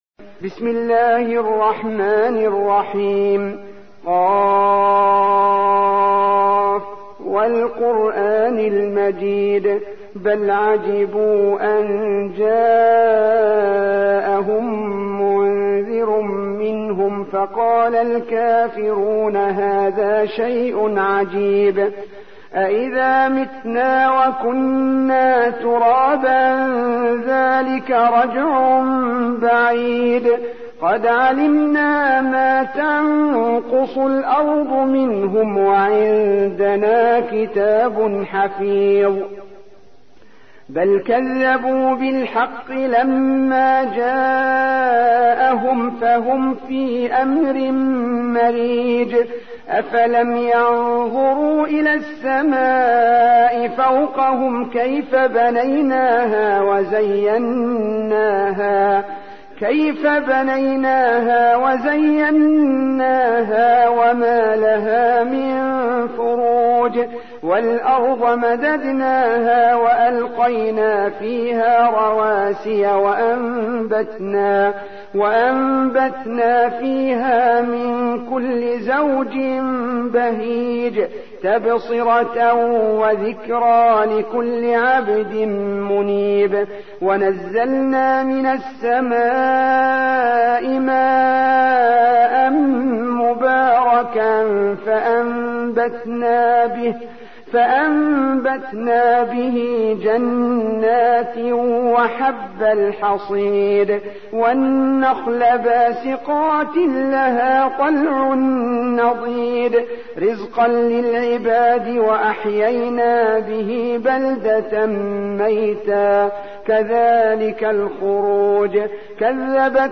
تحميل : 50. سورة ق / القارئ عبد المنعم عبد المبدي / القرآن الكريم / موقع يا حسين